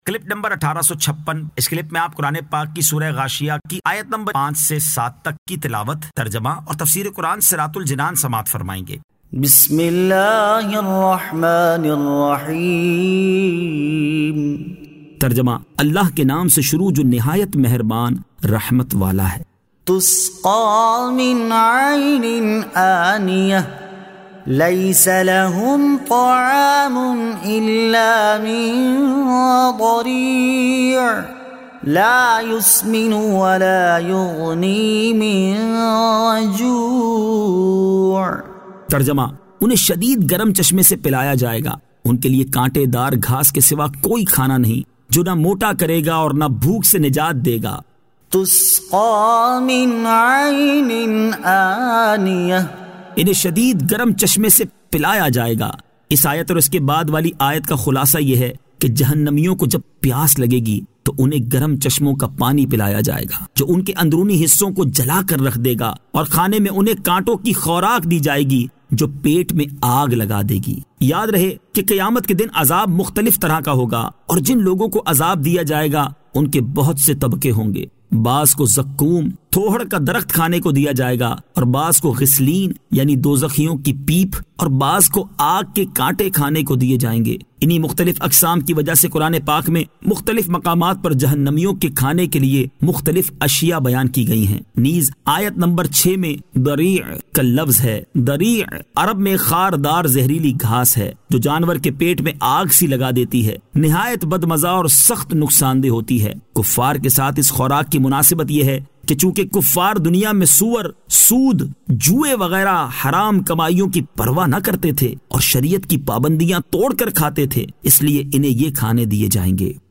Surah Al-Ghashiyah 05 To 07 Tilawat , Tarjama , Tafseer